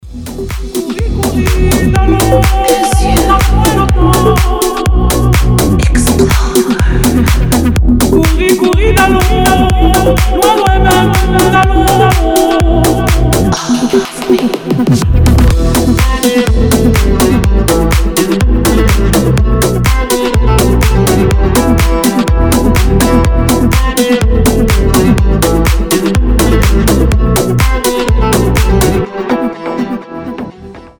• Качество: 320, Stereo
красивые
deep house
чувственные
восточные